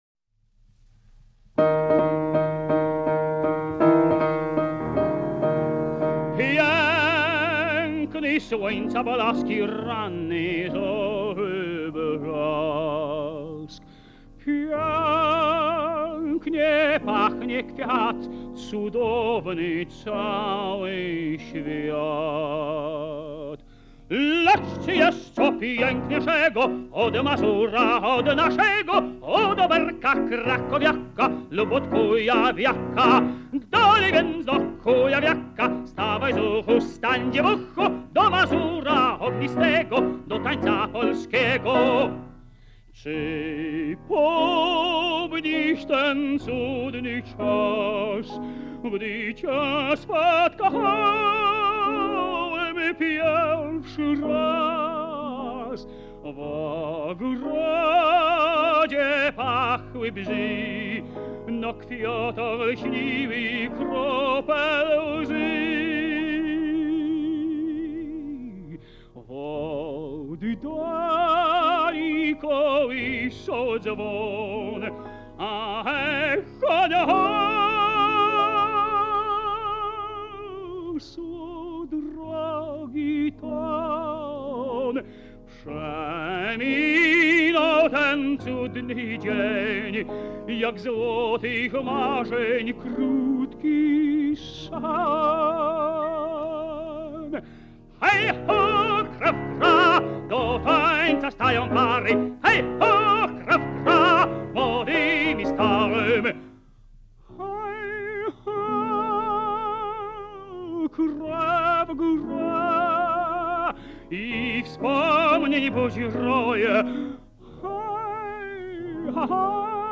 słynny tenor